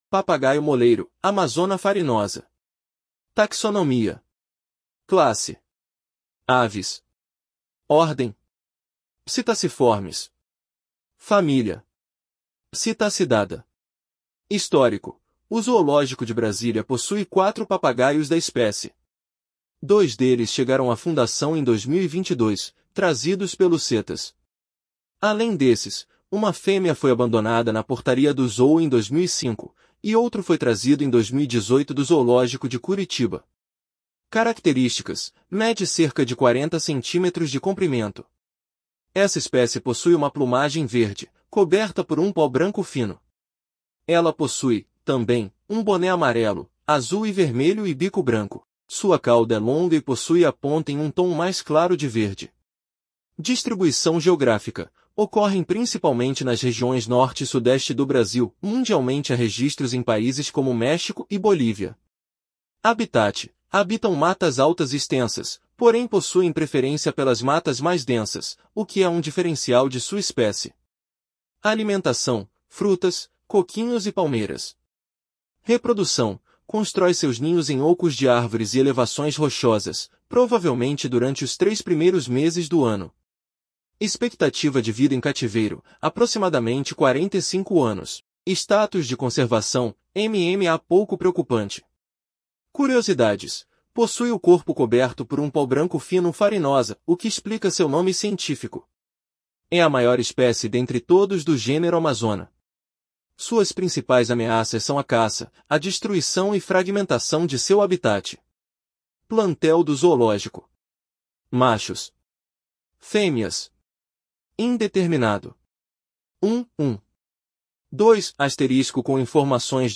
Papagaio-moleiro (Amazona farinosa)